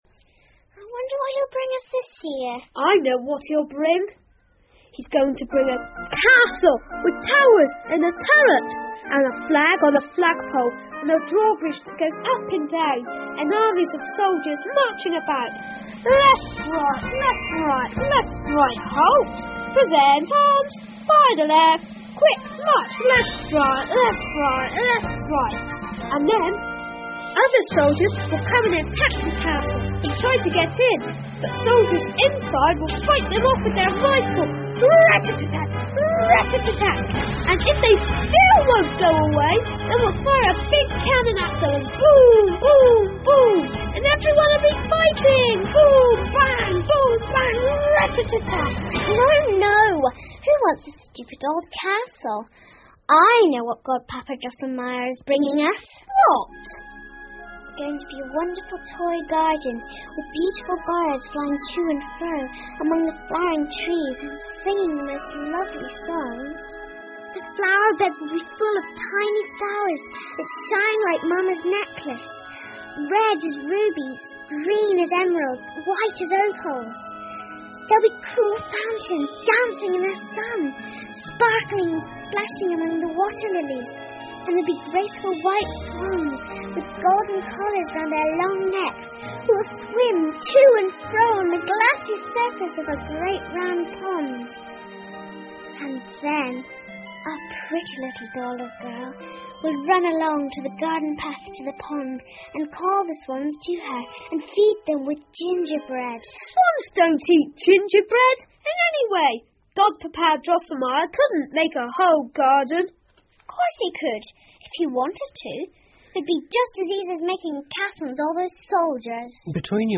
胡桃夹子和老鼠国王 The Nutcracker and the Mouse King 儿童广播剧 2 听力文件下载—在线英语听力室